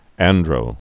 (ăndrō)